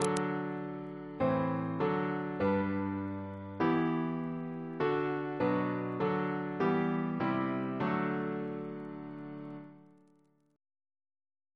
Single chant in D Composer: Sydney H. Nicholson (1875-1947) Reference psalters: ACP: 59; PP/SNCB: 227; RSCM: 199